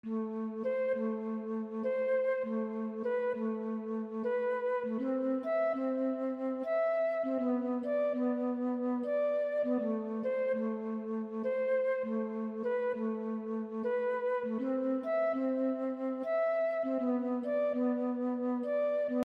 长笛 100bpm
描述：长笛嘻哈音乐旋律/节奏
Tag: 100 bpm Hip Hop Loops Flute Loops 3.23 MB wav Key : Unknown